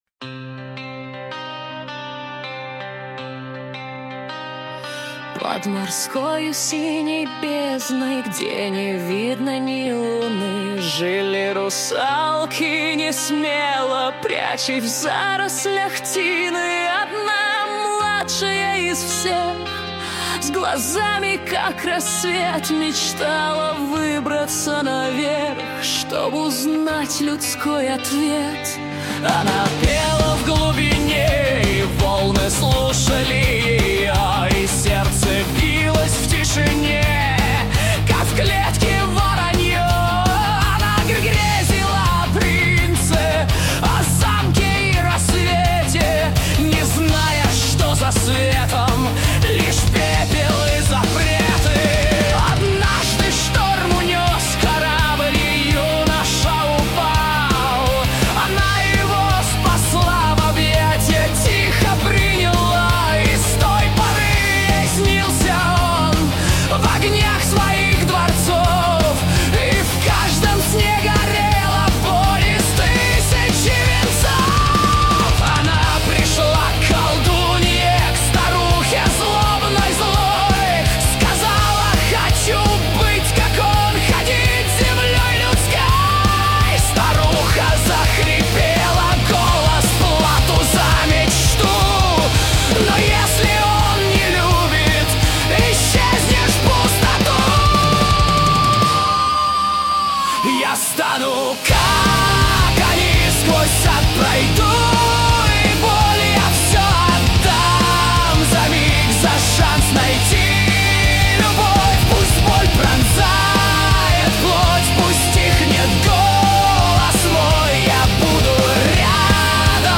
Исполнение - ИИ